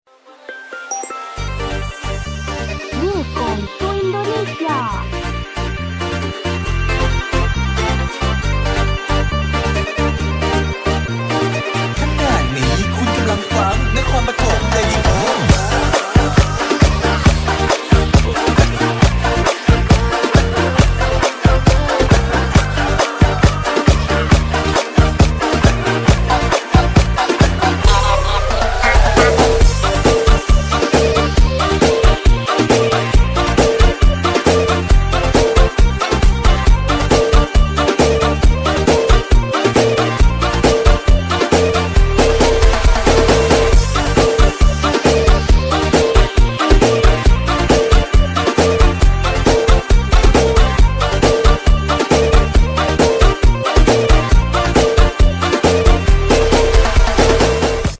Genre: Nada dering viral TikTok